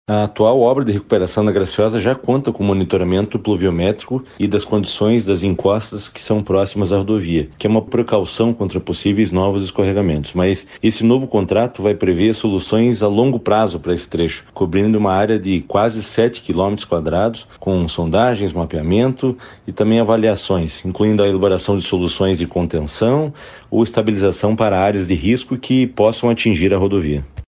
Sonora do diretor-presidente do DER/PR, Fernando Furiatti, sobre o edital para mapear áreas de risco da Estrada da Graciosa